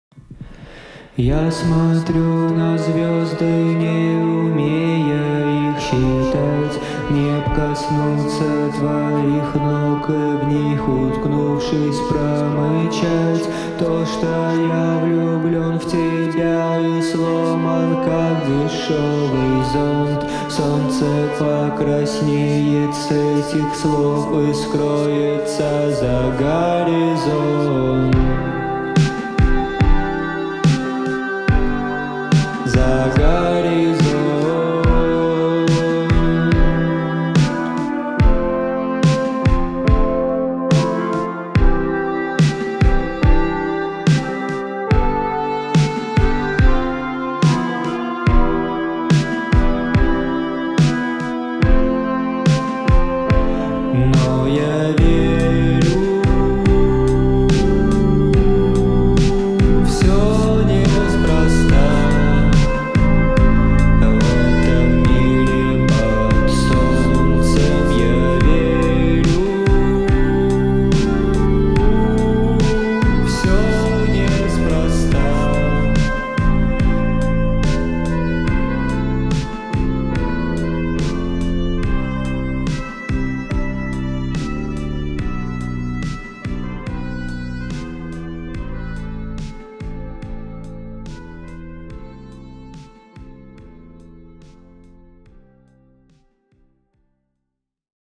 Трек размещён в разделе Поп / 2022 / Казахская музыка.